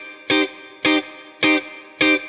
DD_StratChop_105-Cmin.wav